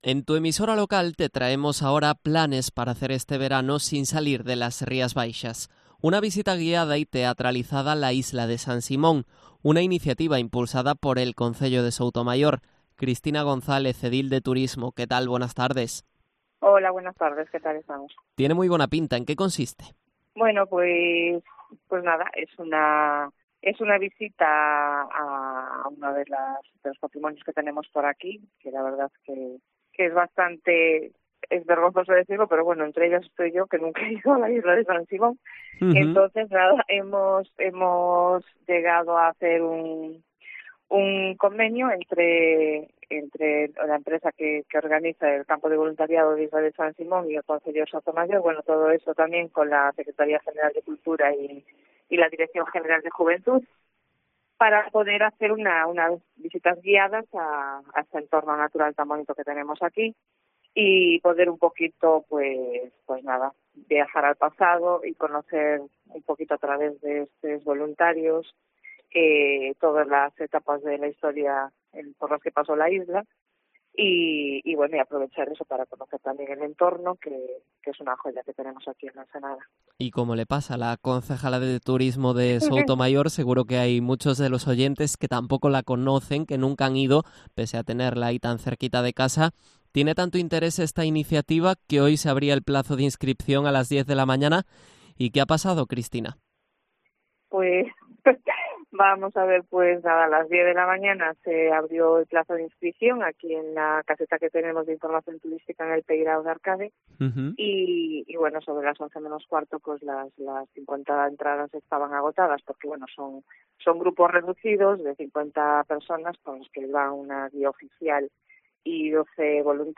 AUDIO: Entrevista a Cristina González, edil de Turismo de Soutomaior